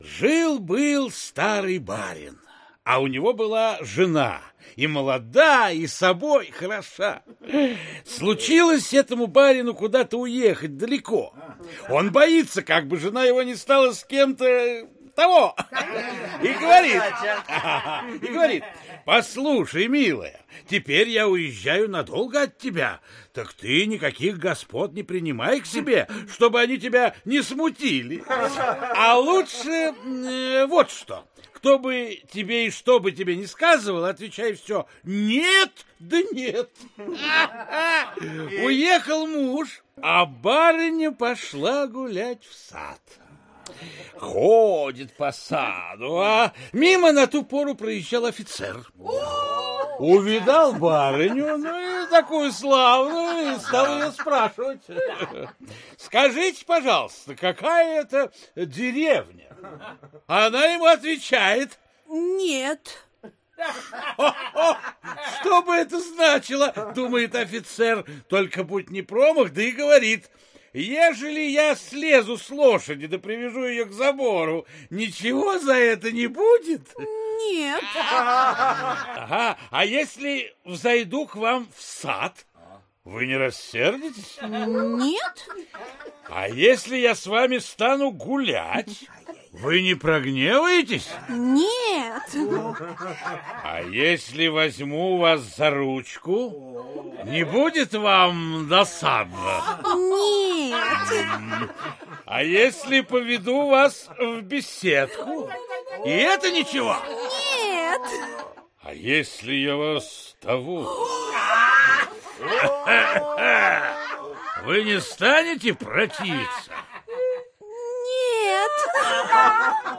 Аудиокнига Кладовая сказок | Библиотека аудиокниг